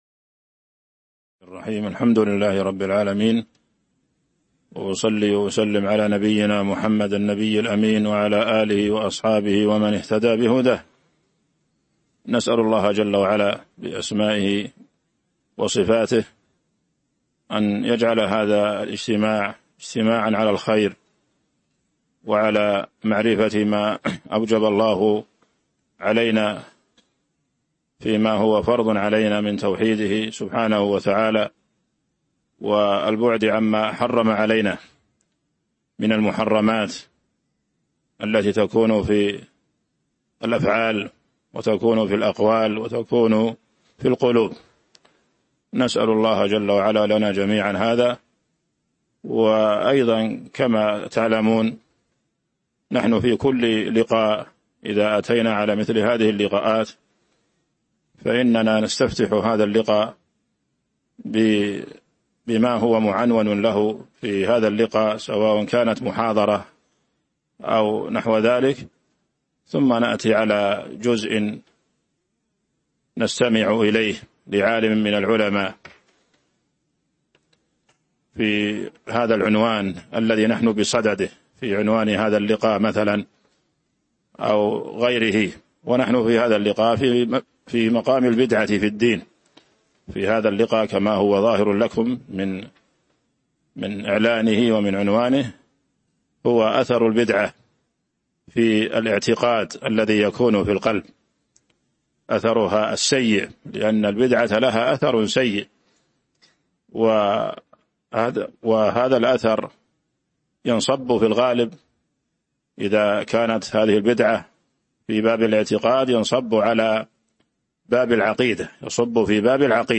تاريخ النشر ١٩ رجب ١٤٤٥ هـ المكان: المسجد النبوي الشيخ